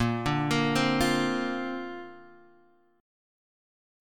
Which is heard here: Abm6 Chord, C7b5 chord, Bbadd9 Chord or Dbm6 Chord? Bbadd9 Chord